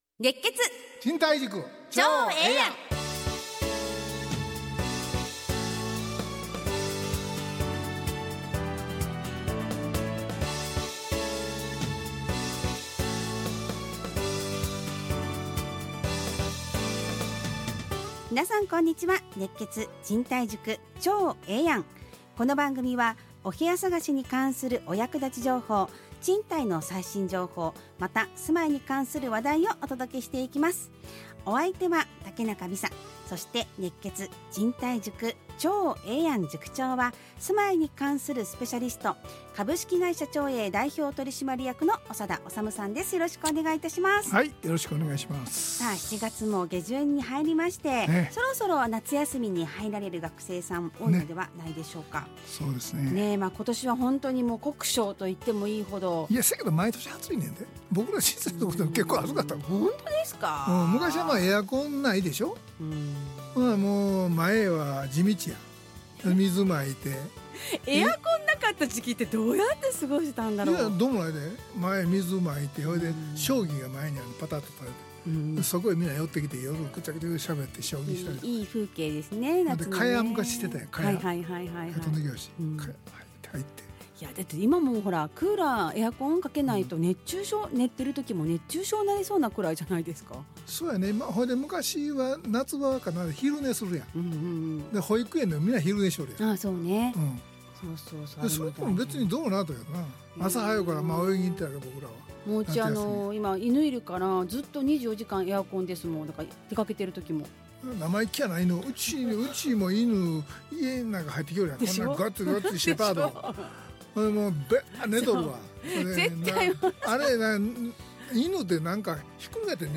ラジオ放送 2025-07-28 熱血！